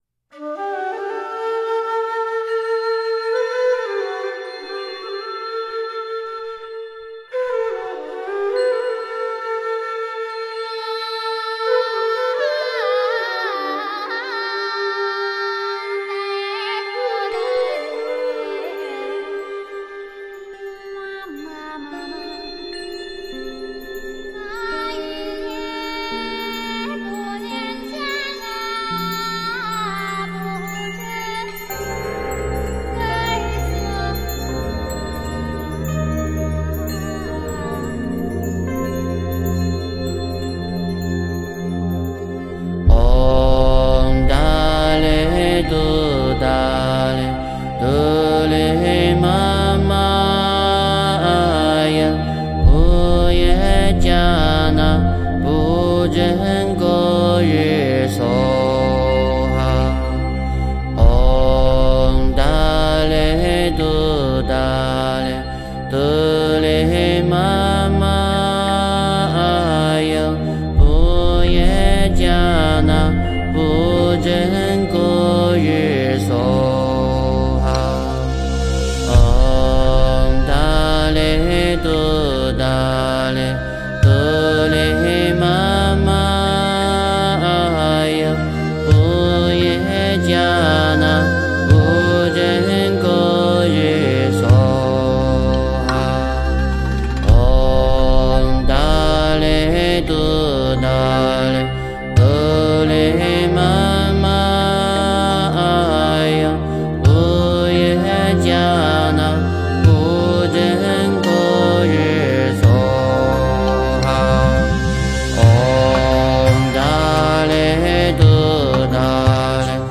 诵经
佛音 诵经 佛教音乐 返回列表 上一篇： Way To Kundalini 下一篇： 称念赞咏七如来 相关文章 大悲咒-梵语 大悲咒-梵语--元音老人... 50.修养身心--佚名 50.修养身心--佚名...